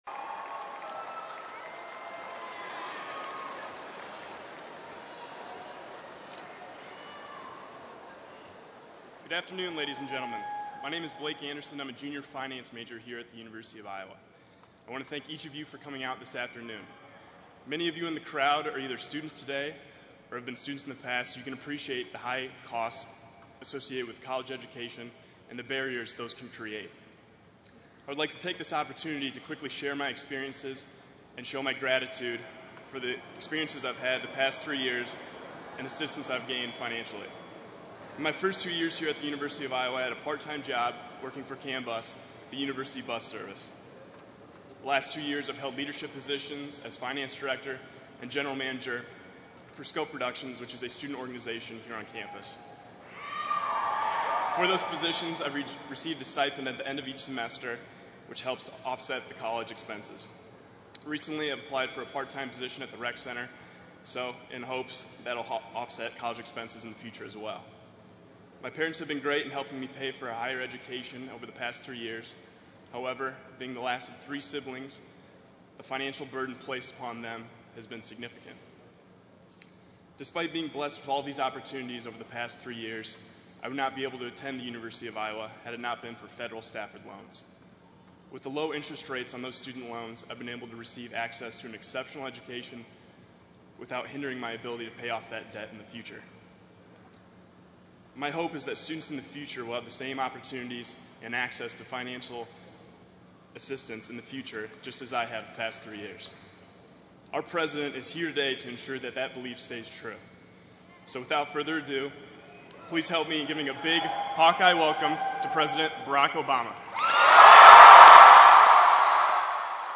President Barack Obama brought his college tour to the University of Iowa today where he talked about student loan interest rates. A group of students clad in black and gold Hawkeye gear stood behind the president as he opened his speech with a football reference.